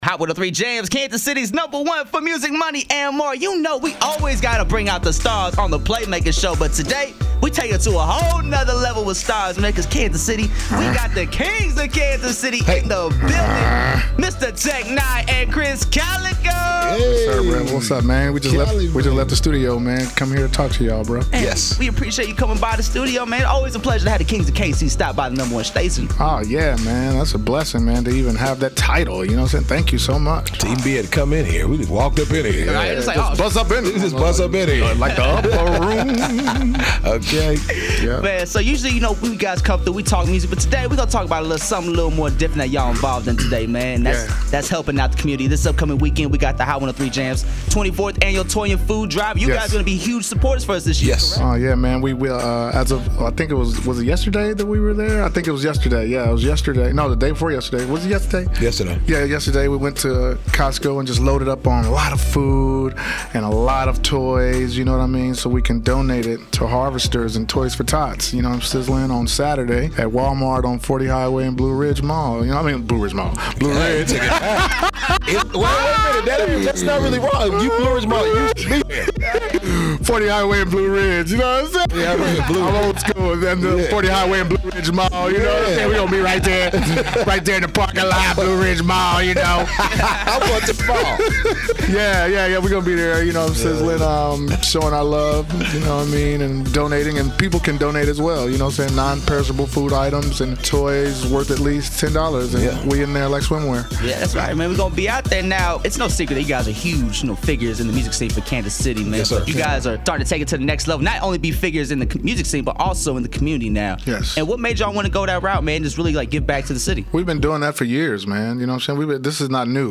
In-studio interview: Tech N9ne & Krizz Kaliko!
The Kings of Kansas City stopped by the Playmaker show to discuss their work in the community and this weekend’s Hot 103 Jamz Toy & Food Drive. Also discussed Tech’s latest upcoming album “Planet” and what winning a Grammy would mean for Tech, Krizz and Strange Music! Check out the full unedited interview now!